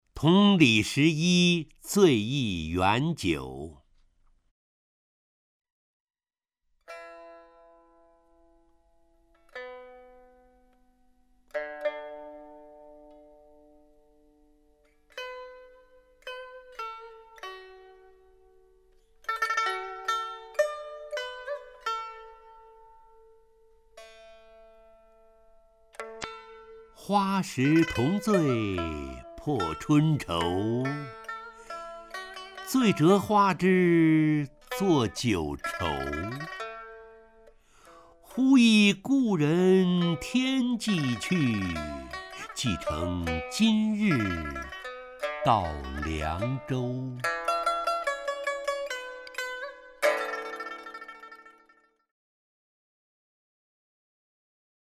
陈醇朗诵：《同李十一醉忆元九》(（唐）白居易) （唐）白居易 名家朗诵欣赏陈醇 语文PLUS